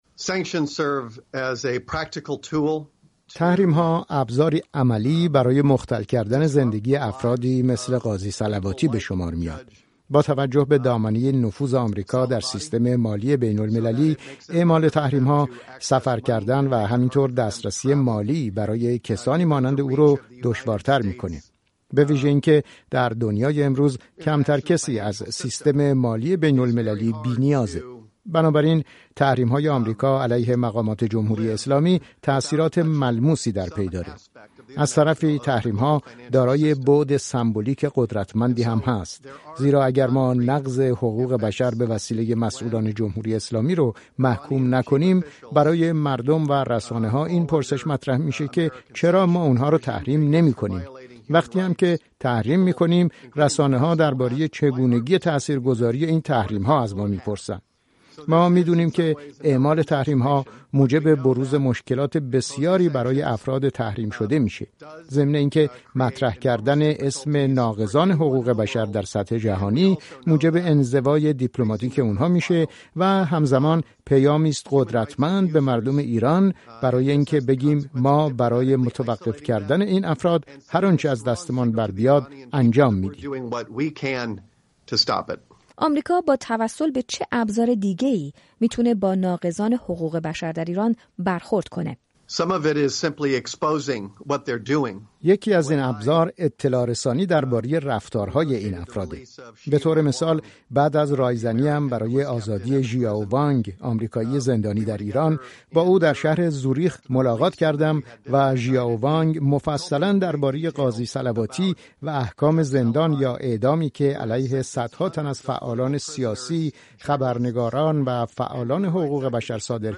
مصاحبه اختصاصی رادیو فردا با برایان هوک، نماینده ویژه آمریکا در امور ایران، در حاشیه تحریم دو قاضی ایرانی